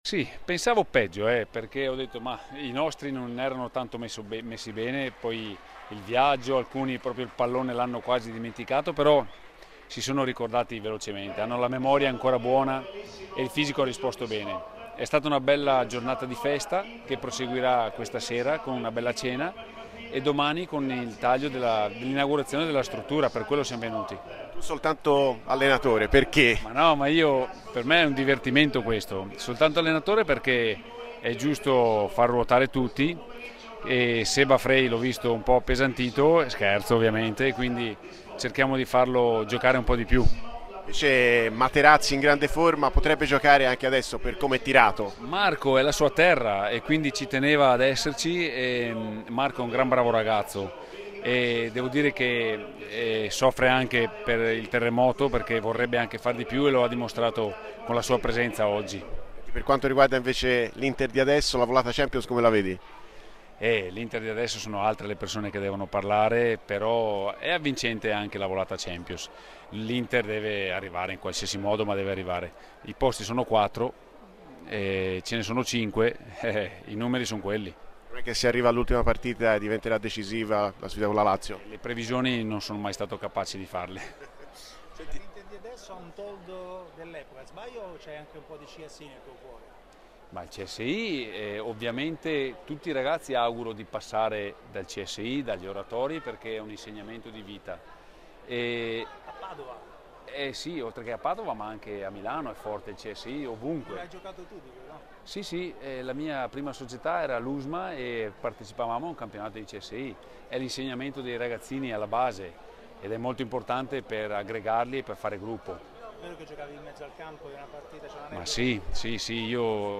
Francesco Toldo, ex giocatore, intervistato
a margine dell'appuntamento a Tolentino di Inter Forever